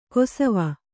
♪ kosava